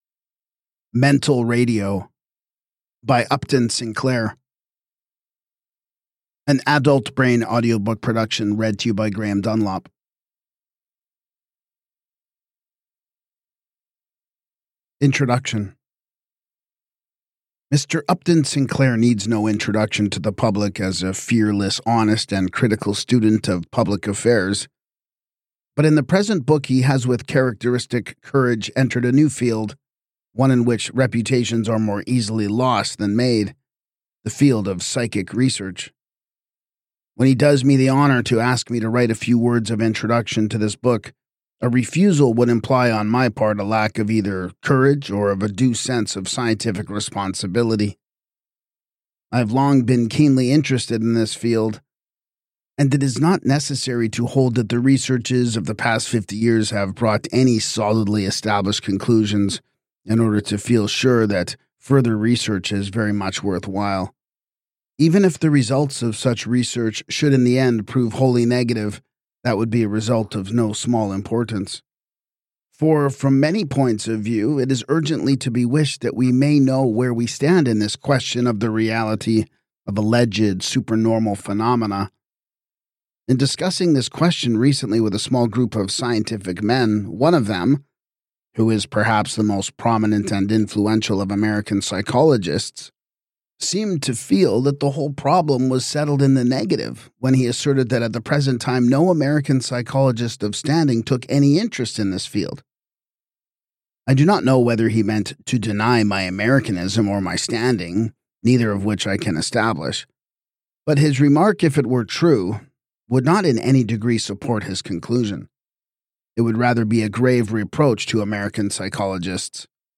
A Philosophy and Psychology Audiobook on Attention, Thought, and Mental Discipline
Designed for listeners interested in philosophy audiobooks, psychology audiobooks, and intellectual self-development, Mental Radio offers a calm, structured listening experience without motivational hype or commentary.